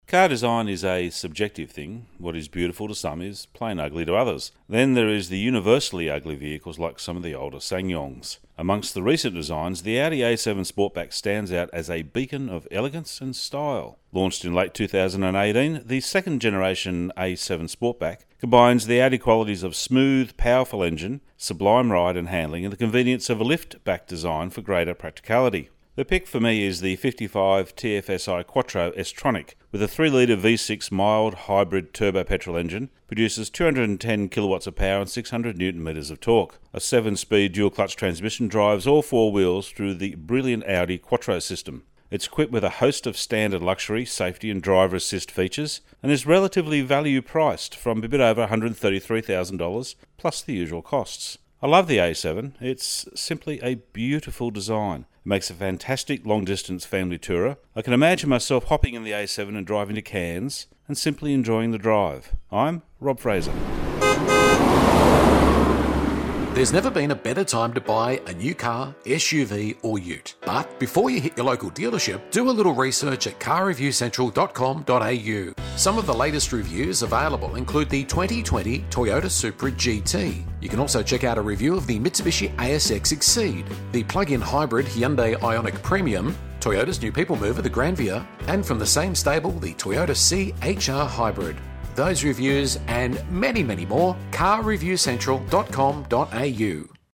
Audi A7 55 TFSI Quattro Sportback Radio Review